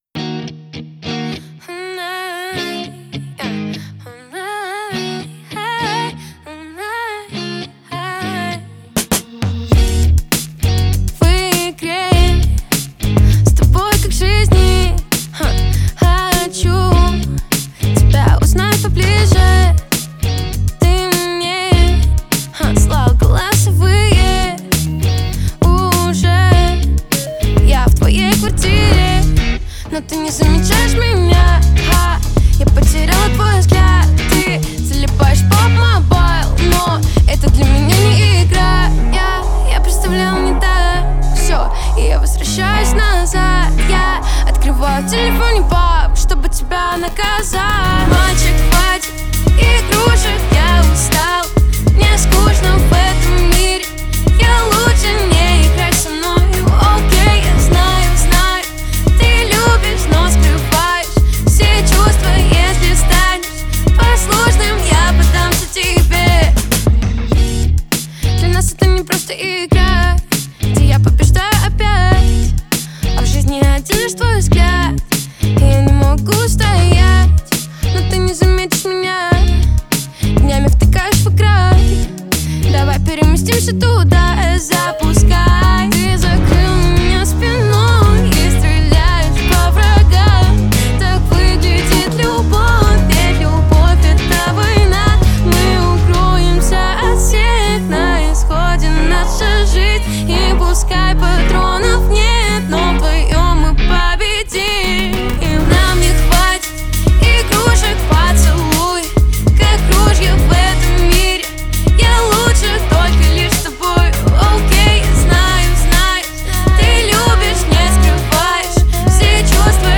атмосферными мелодиями и мягким вокалом